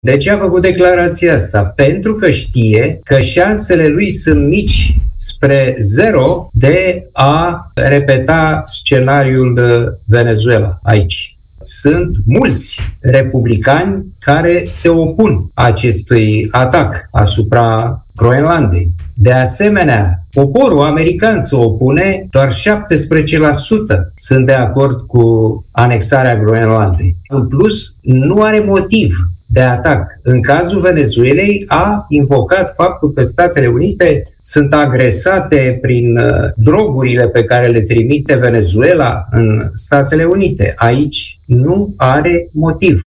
Statele Unite nu vor folosi forța pentru a cuceri Groenlanda, dar ar fi de neoprit dacă ar face-o, a avertizat Donald Trump, la forumul economic de la Davos. Invitat în emisiunea „Deșteptarea”, scriitorul și gazetarul Cristian Tudor Popescu a spus că nu putem ști niciodată ce va spune sau ce va face mâine președintele Statelor Unite.